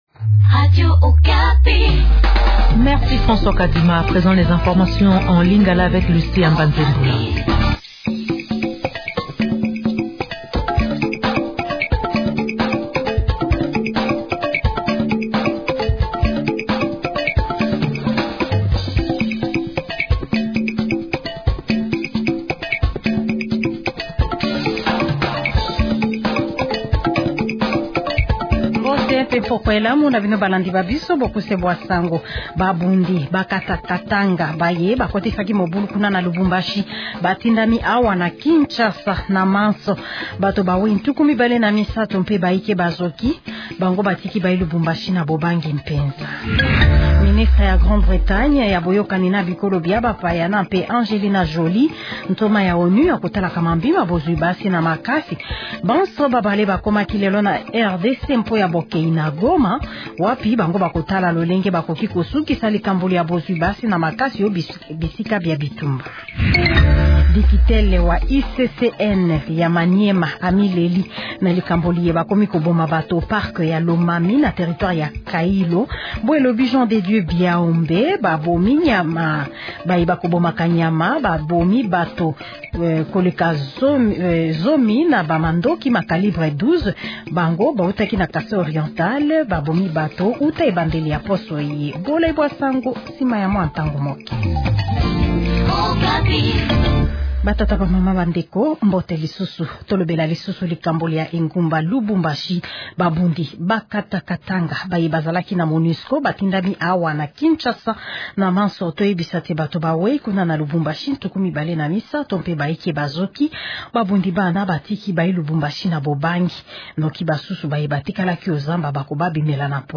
Journal Lingala Soir